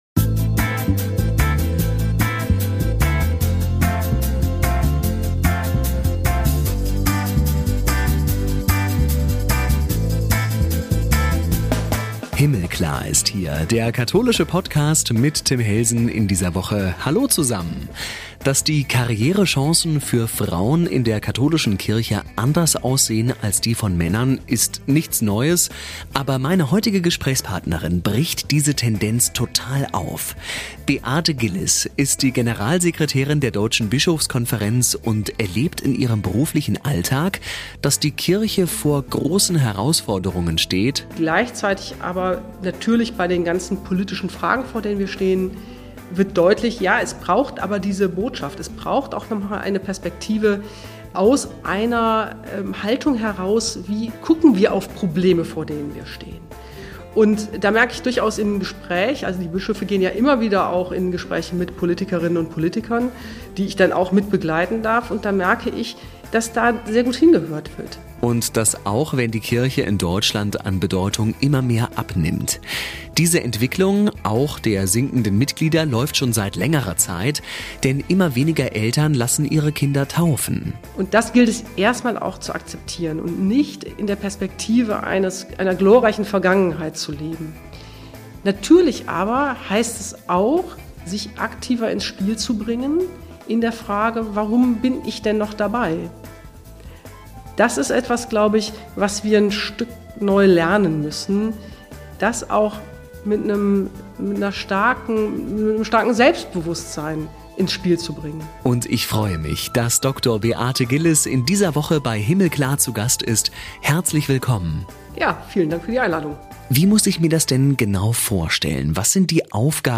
Mit wöchentlich spannenden Gesprächen und Inspiration von Menschen aus ganz unterschiedlichen Ecken aus Kirche und Welt bringen wir unsere Stimme in den gesellschaftlichen Dialog ein.